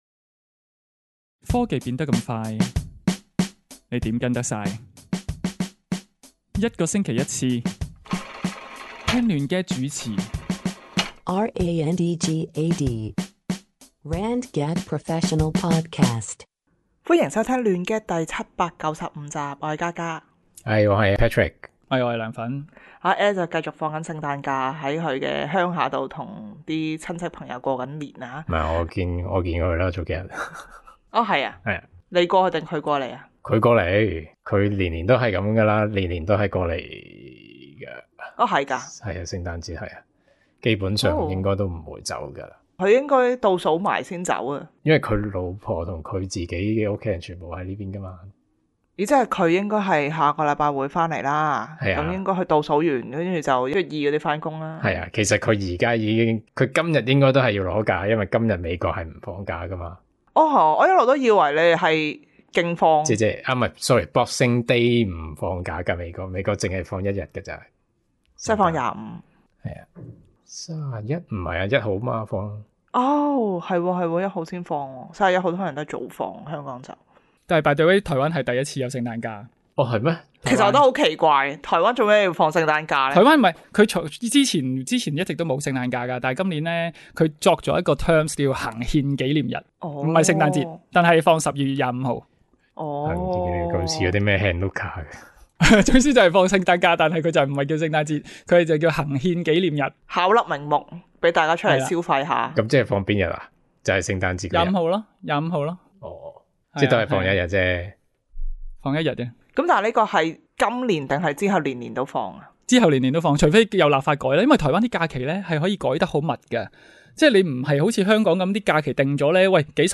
搜羅最新科技資訊、數碼產品，由四位主持，從不同立場出發，以專業角度分析，每星期一集既網上電台節目 - 亂gad！